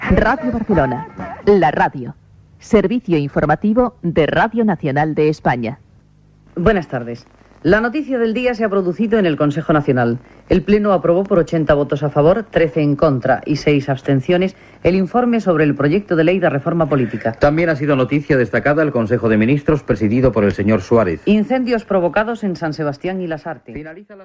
Identificació de l'emissora i connexió amb Radio Nacional de España per emetre les notícies: el ple del Consejo Nacional del Movimiento aprova la llei de reforma política
Informatiu